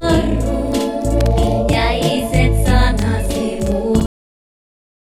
Chorus/flanger
Industry standard chorus: delay 40 ms, modulation frequency 0.3 Hz, modulation depth 20 ms, feedback 0, feedforward 0.7071, blend 1